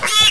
pig_ko.wav